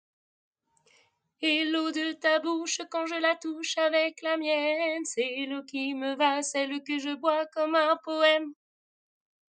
Retrouver nos chants, nos enregistrements, pour chanter entre femmes à Gap
A refaire à l'occas (c'est pas très juste!!)